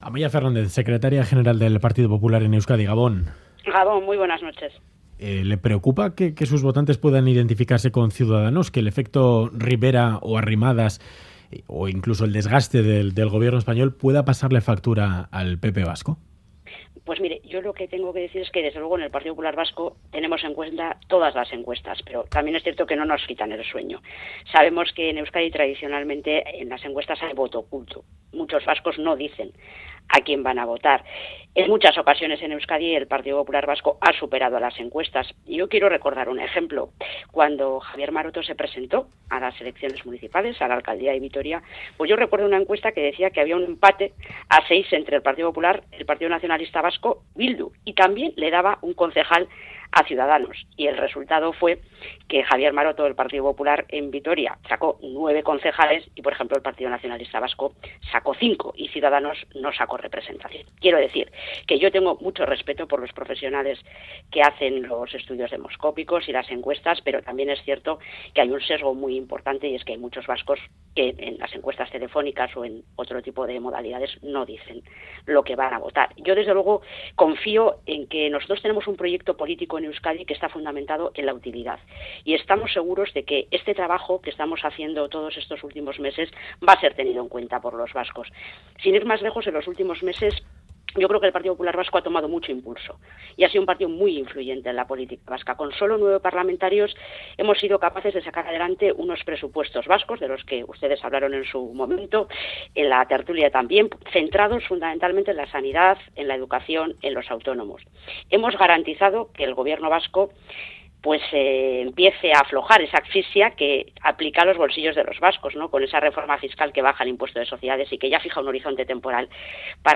Radio Euskadi GANBARA 'La propuesta del Departamento de Seguridad es un parche' Última actualización: 06/03/2018 22:55 (UTC+1) Entrevista a Amaya Fernández, Secretaria General del Partido Popular vasco.